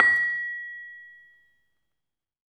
LAMEL A#5 -L.wav